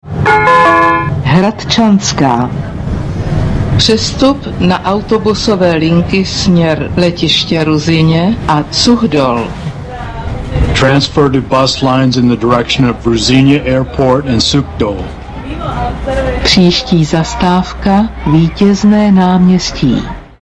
- Hlášení o výluce v tramvajích (přestup) si